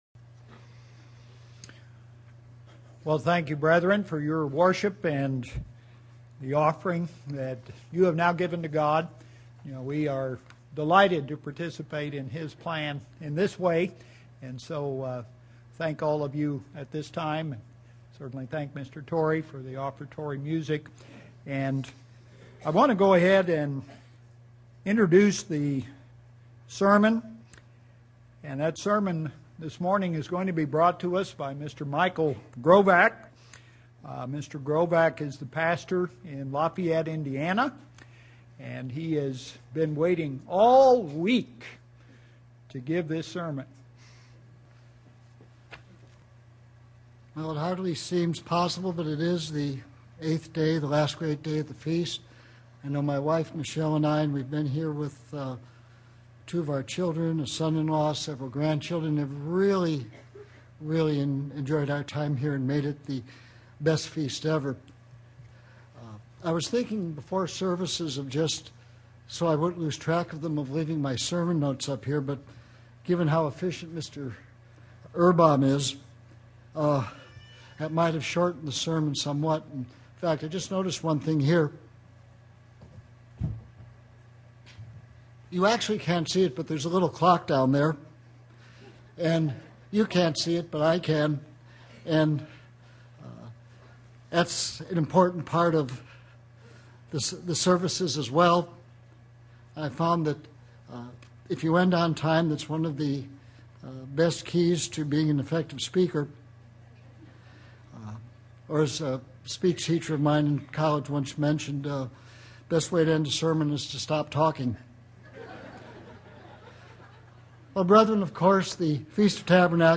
This sermon was given at the Branson, Missouri 2012 Feast site.